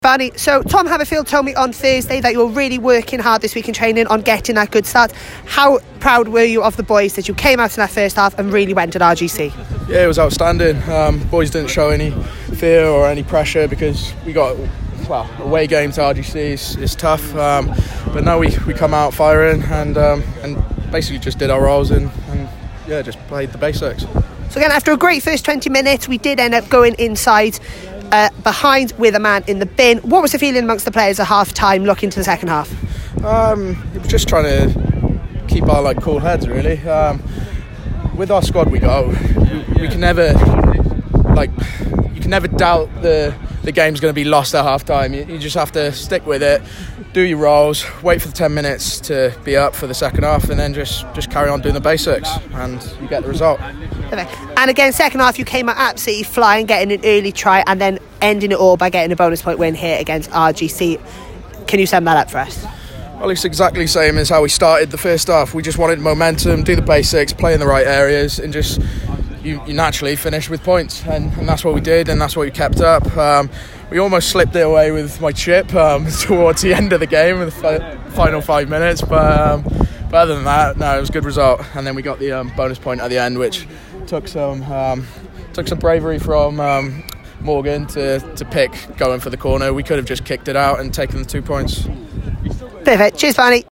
Post Match Interviews.